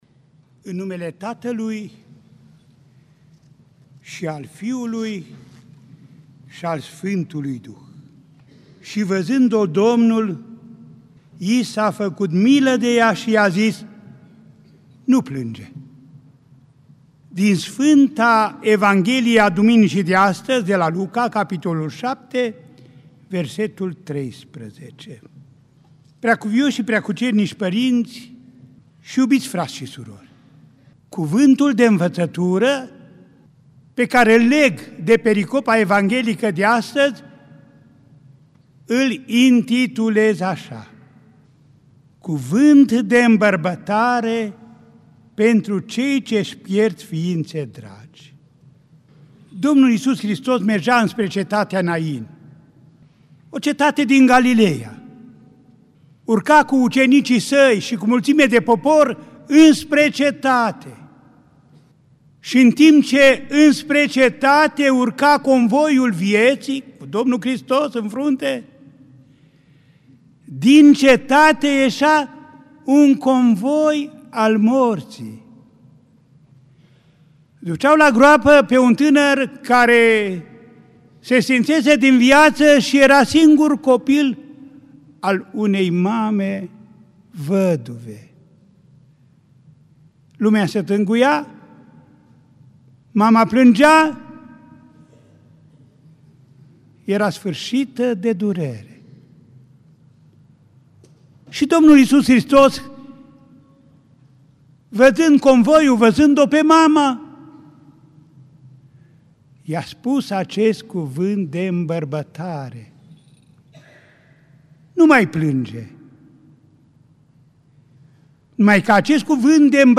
oct. 8, 2017 | Predici IPS Andrei